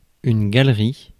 Ääntäminen
Synonyymit porte-bagage musée parterre Ääntäminen France: IPA: [ɡal.ʁi] Haettu sana löytyi näillä lähdekielillä: ranska Käännöksiä ei löytynyt valitulle kohdekielelle.